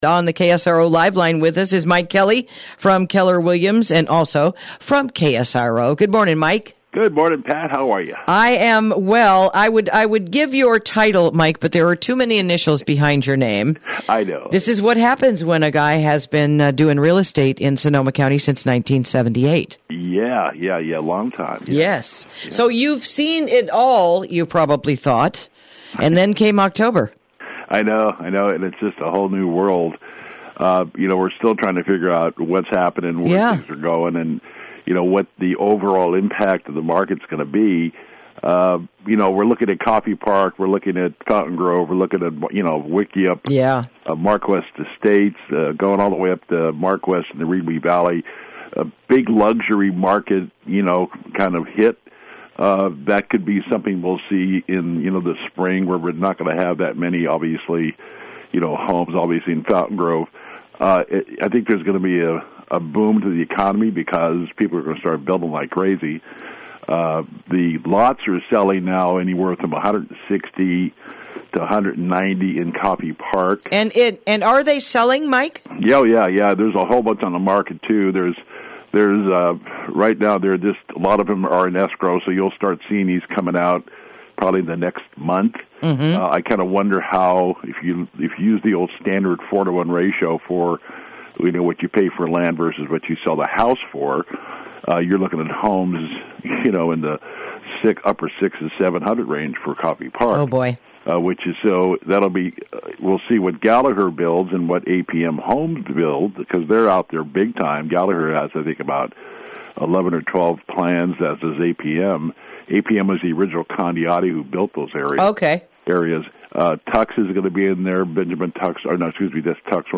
Interview: What Will Real Estate Look Like in 2018 | KSRO 103.5FM 96.9FM & 1350AM